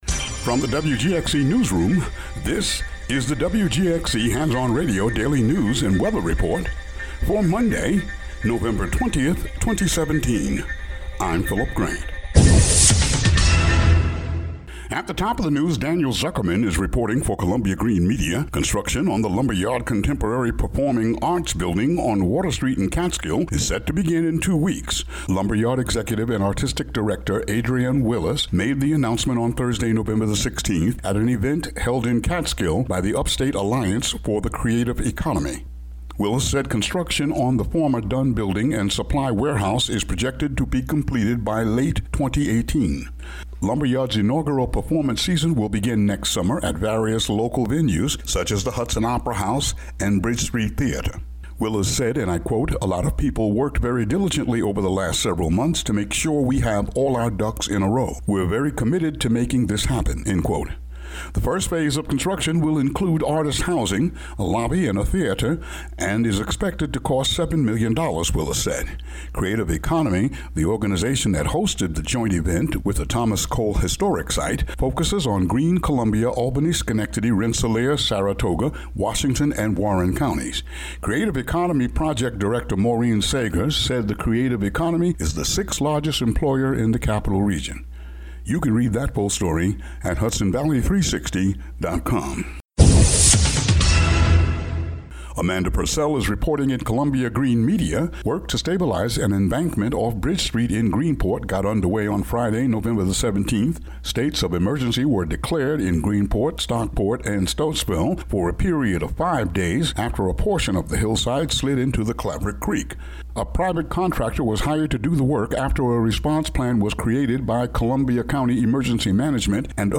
Local news for Nov. 20, 2017.